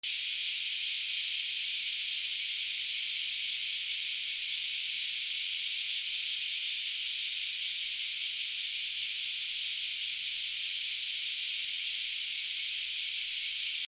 / B｜環境音(自然) / B-25 ｜セミの鳴き声 / セミの鳴き声_20_クマゼミ
14 クマゼミ 多め